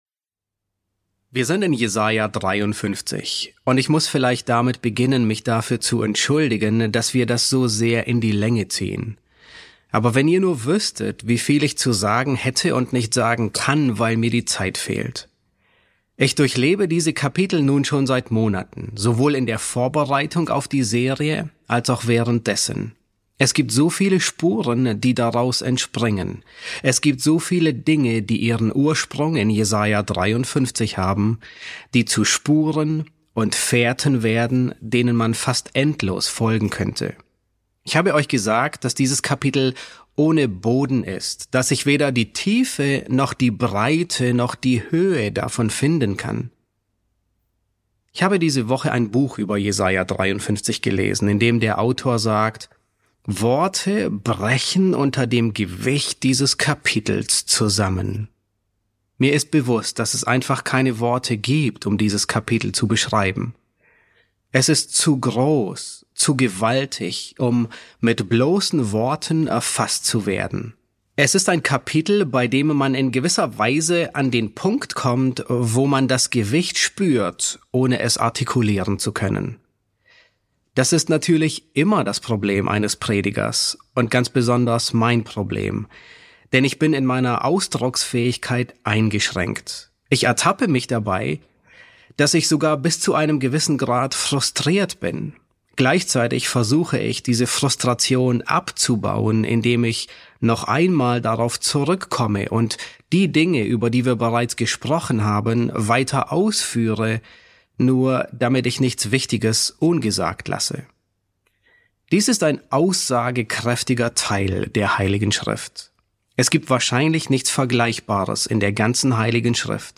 S4 F8 | Der stumme Knecht, Teil 2 ~ John MacArthur Predigten auf Deutsch Podcast